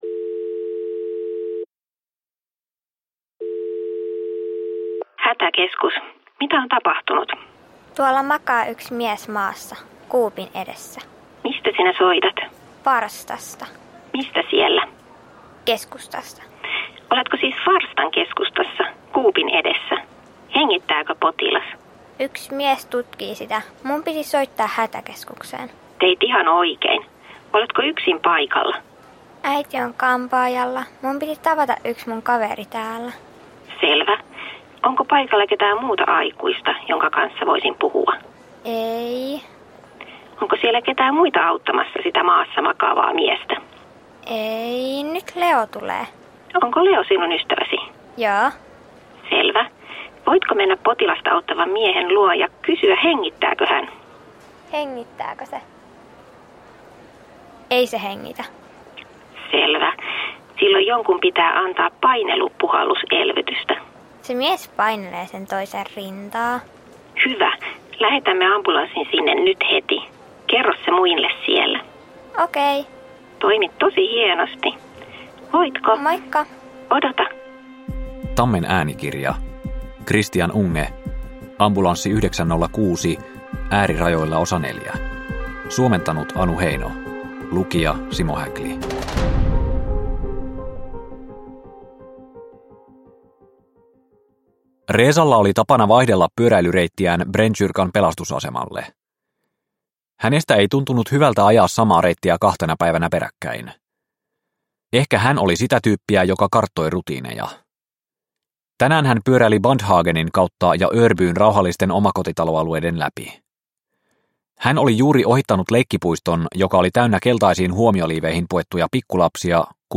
Ambulanssi 906 Osa 4 – Ljudbok – Laddas ner